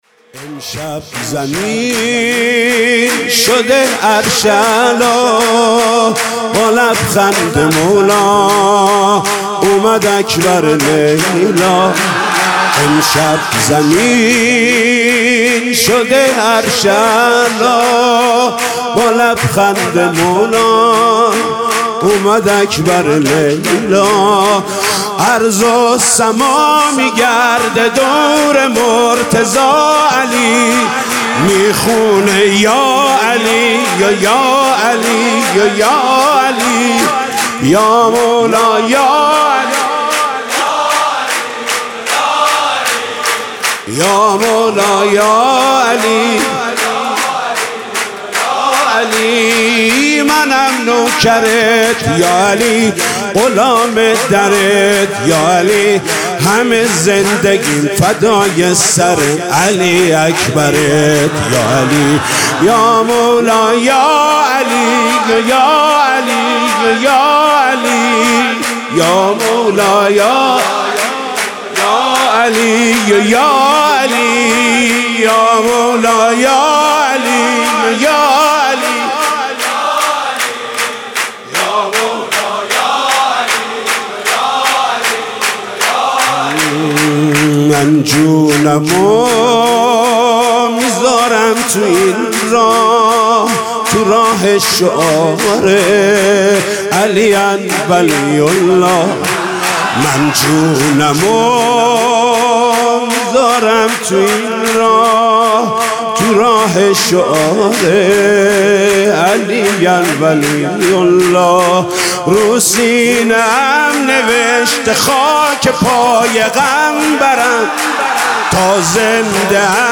سرود: امشب زمین شده عرش اعلا